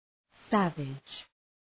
{‘sævıdʒ}